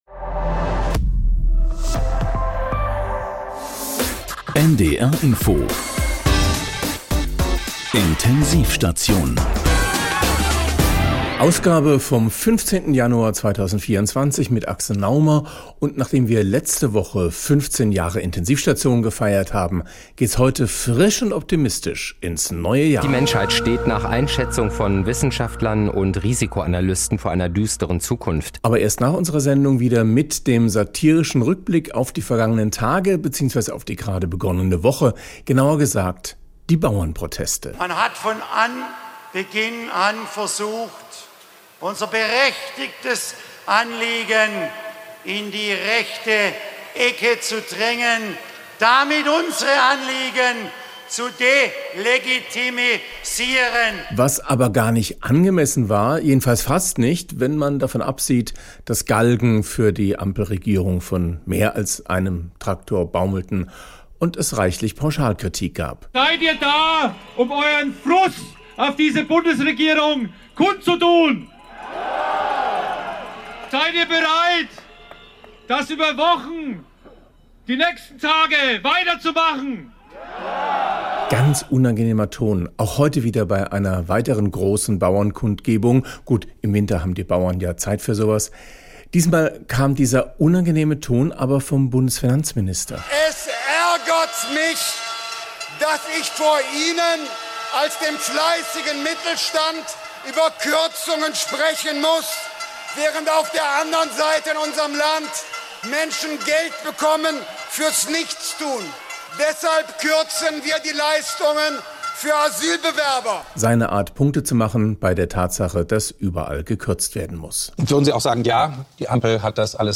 Wie sich ihr Höhenflug anfühlt und wie sie es mit der AfD hält, erklärt sie im Exklusiv-Interview.